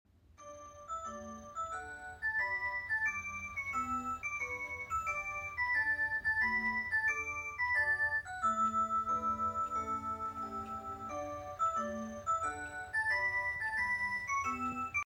this clock has such a sound effects free download
this clock has such a cute sound 🥰